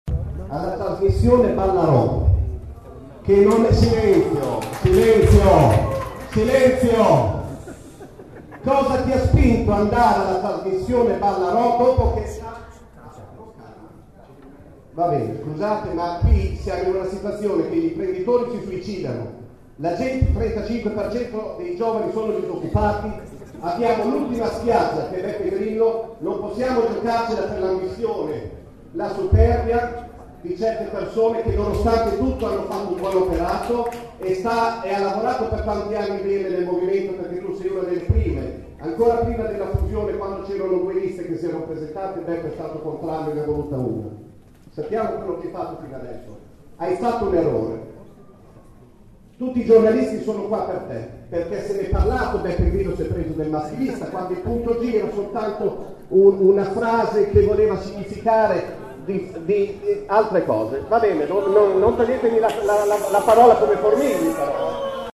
Ma già la seconda domanda fatta dal pubblico è entrata nella questione della partecipazione a Ballarò: “Cosa ti ha spinto ad andare alla trasmissione?” ha chiesto un cittadino all’indirizzo di Salsi
La domanda